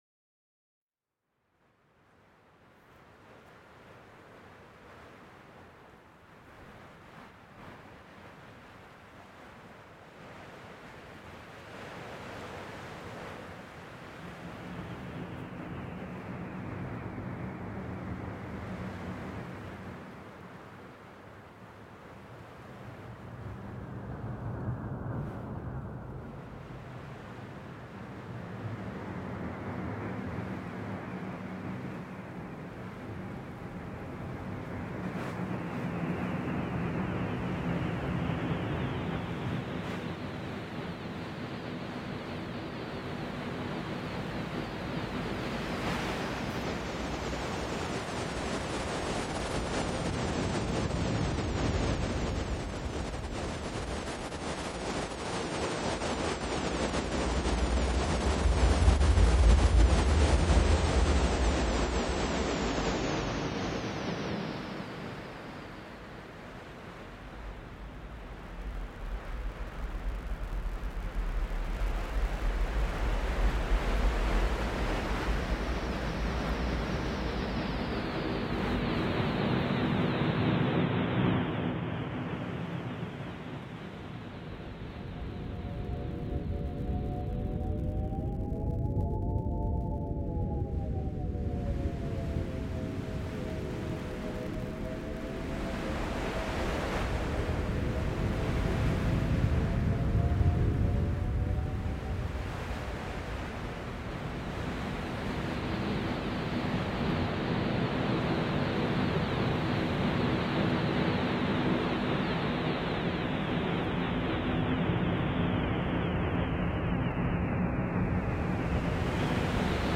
Strong winds overlooking a cliff face